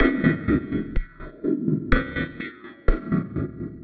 tx_perc_125_clangpulse.wav